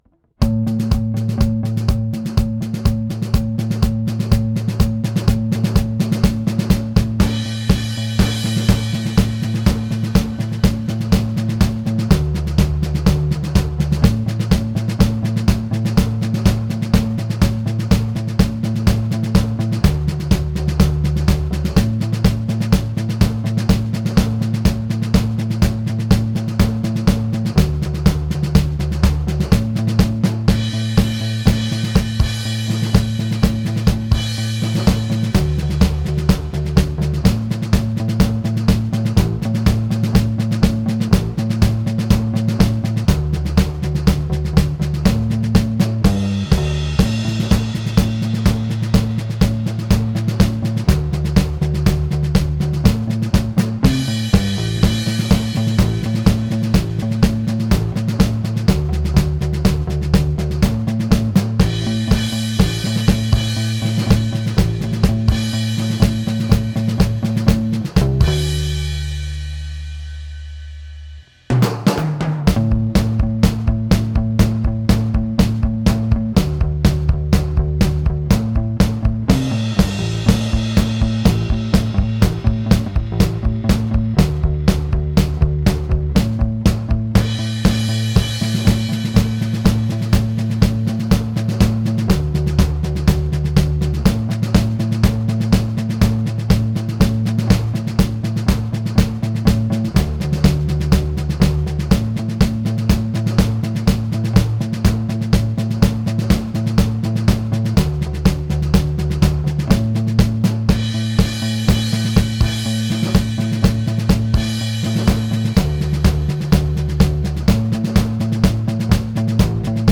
bass 3:12 Not perfect, but good enough for the 2nd take